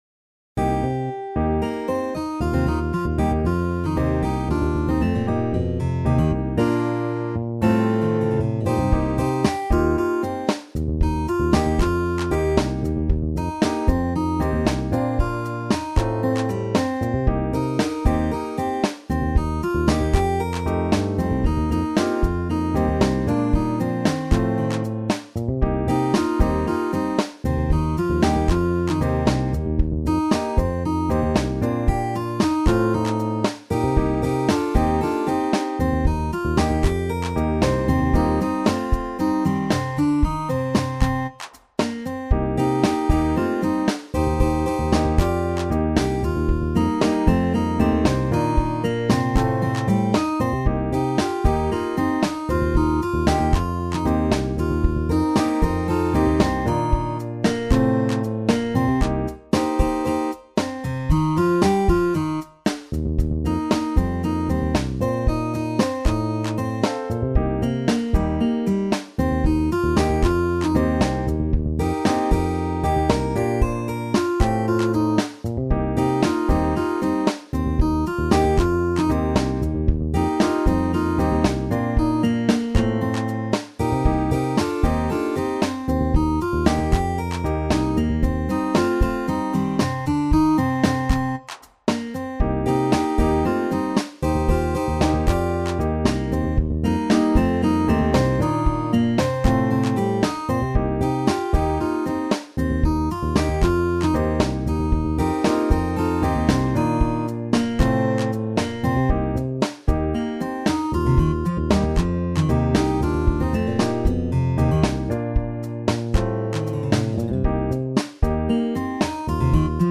SSATTB met piano
gearrangeerd met de mannen versus de vrouwen